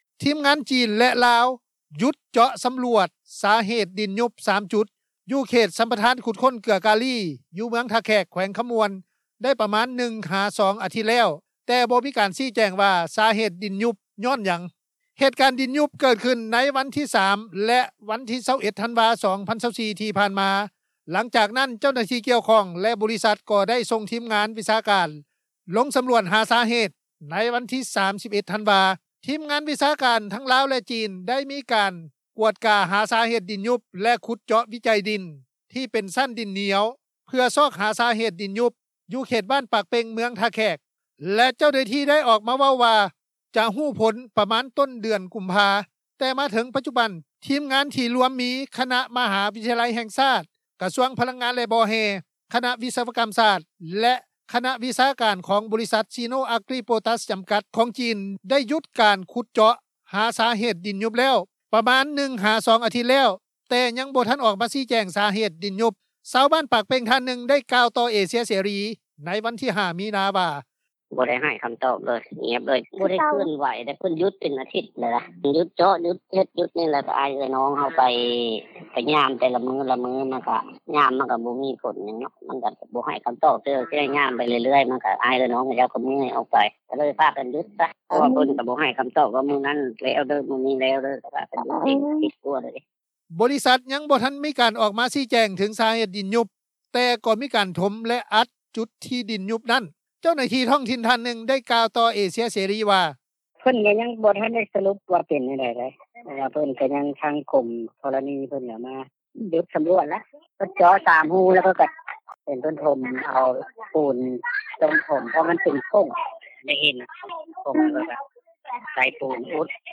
ແຕ່ມາຮອດປັດຈຸບັນ ທີມງານ ທີ່ລວມມີຄະນະມະຫາວິທະຍາໄລແຫ່ງຊາດ, ກະຊວງພະລັງງານ ແລະ ບໍ່ແຮ່, ຄະນະວິສະວະກໍາສາດ ແລະຄະນະວິຊາການ ຂອງບໍລິສັດ ຊີໂນ-ອາກຣີ ໂປຕາສ ຈໍາກັດ ຂອງຈີນ ກໍໄດ້ຢຸດການຂຸດເຈາະ ຫາສາເຫດດິນຍຸບແລ້ວ ໄດ້ປະມານ 1-2 ອາທິດແລ້ວ, ແຕ່ ຍັງບໍ່ທັນອອກມາຊີ້ແຈງສາເຫດດິນຍຸບ. ຊາວບ້ານ ປາກເປ່ງ ທ່ານໜຶ່ງ ໄດ້ກ່າວຕໍ່ວິທຍຸເອເຊັຽເສຣີ ໃນວັນທີ 5 ມີນາ ວ່າ:
ບໍລິສັດ ຍັງບໍ່ທັນໄດ້ອອກມາ ຊີ້ແຈງເຖິງສາເຫດດິນຍຸບ, ແຕ່ໄດ້ມີການຖົມແລະອັດ ຈຸດທີ່ດິນຍຸບນັ້ນ. ເຈົ້າໜ້າທີ່ທ້ອງຖິ່ນ ທ່ານໜຶ່ງ ໄດ້ກ່າວຕໍ່ວິທຍຸເອເຊັຽເສຣີ ວ່າ: